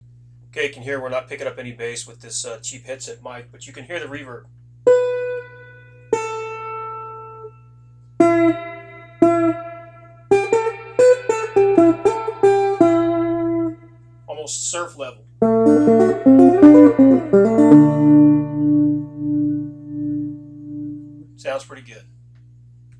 Cut 5: Cut from Reverb Prototype
2-surf_reverb.wav